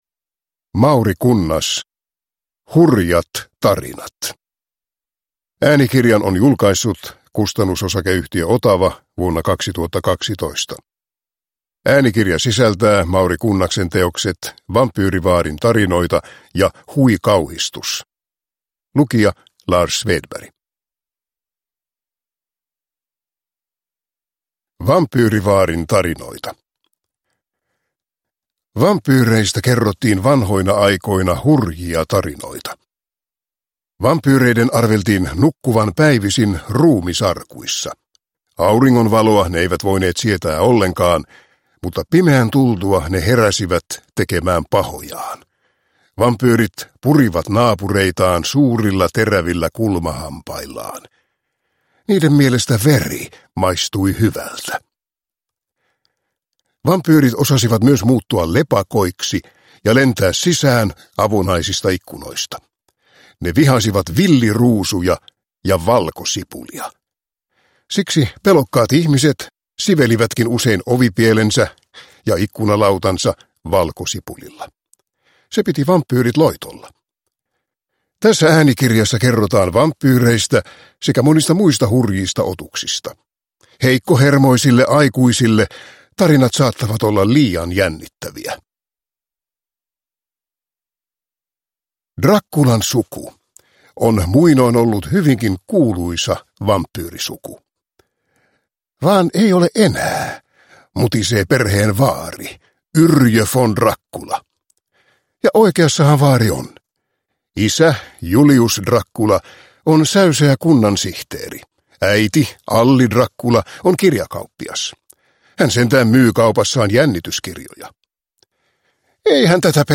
Hurjat tarinat – Ljudbok – Laddas ner